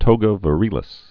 (tōgə və-rēlĭs, -rĭlĭs)